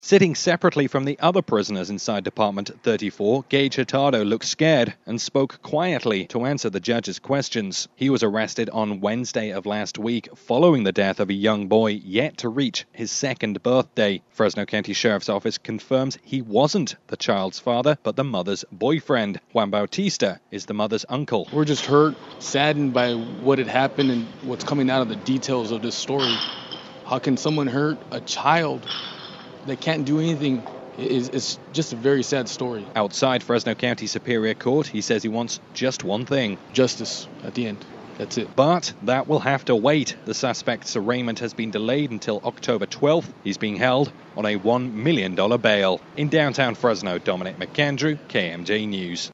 as it aired: